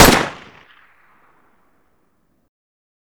aps_shoot.ogg